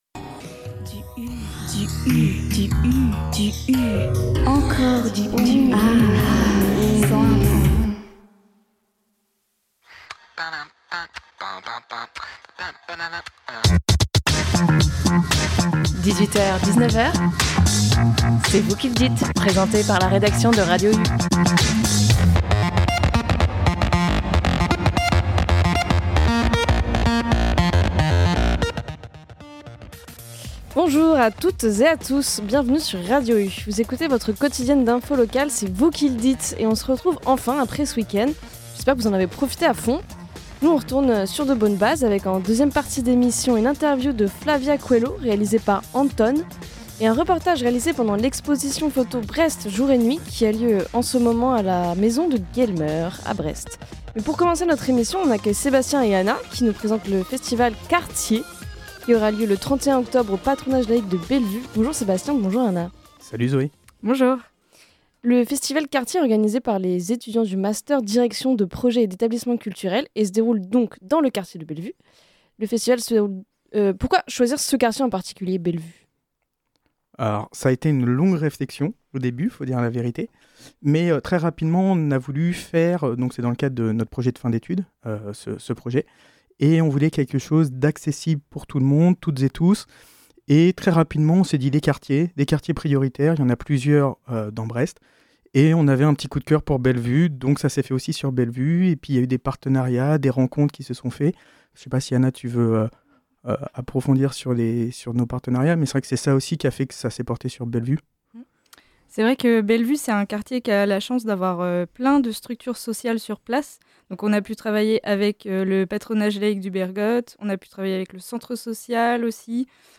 Enfin, une interview de l’artiste Flavia Coelho que l’on retrouvera à la Carène le 8 novembre.